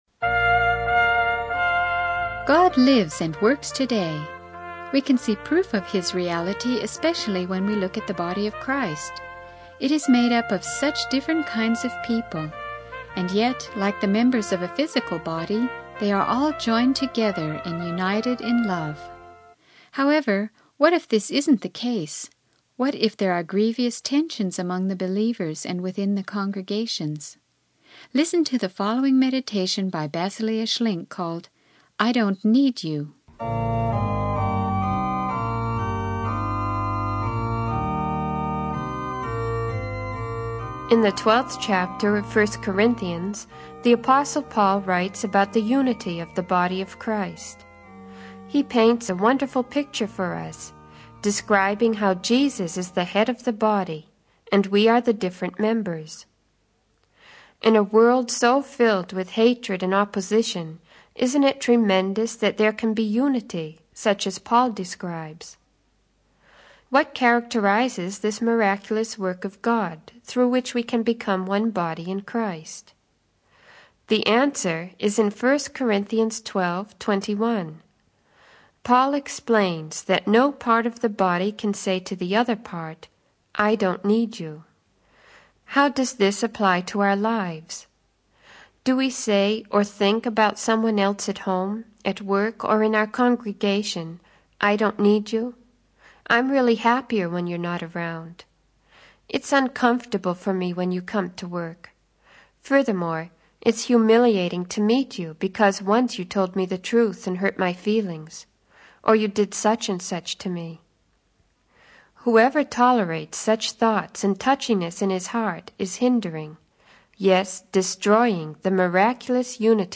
The sermon highlights the necessity of love and unity in the body of Christ, urging believers to embrace one another despite differences.